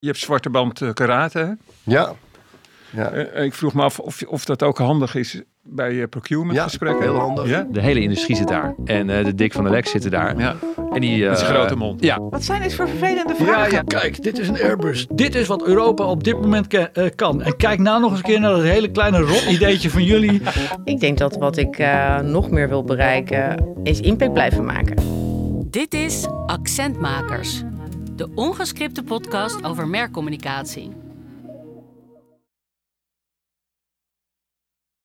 In Accent Makers (coproductie SAN × Earforce / Podcastfactory) hoor je gesprekken met mensen aan merkzijde en bureauzijde over het werk en vooral over de keuzes erachter.